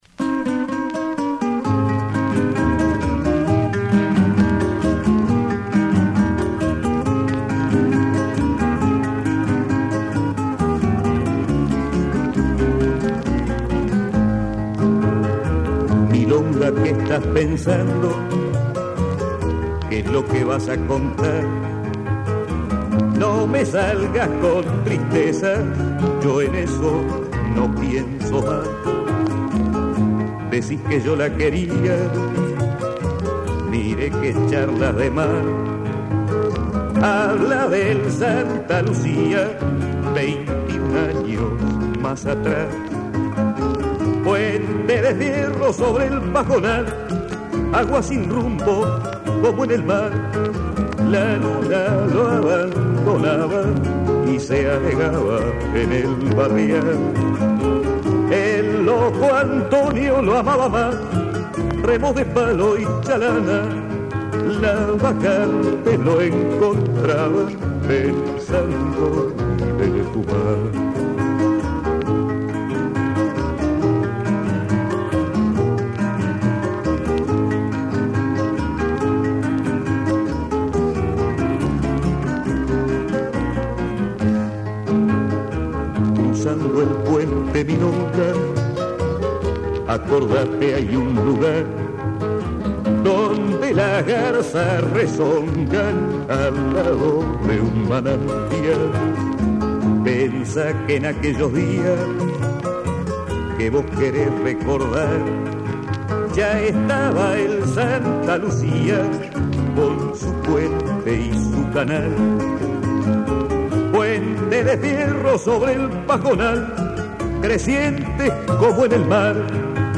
(Milonga)